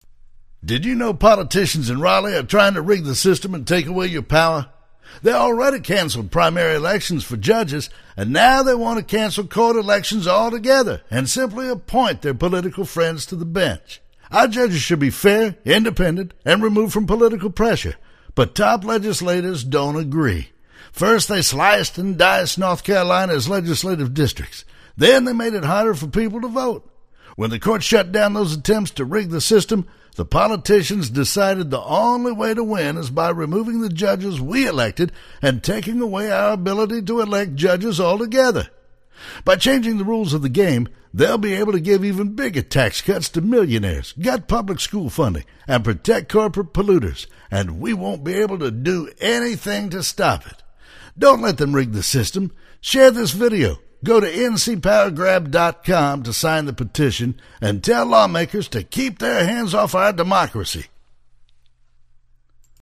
Male
Adult (30-50), Older Sound (50+)
My voice ranges from deep Bass to Baritone.
Political Spots
Southern Gentleman Political
Words that describe my voice are Deep, Southern, Cowboy.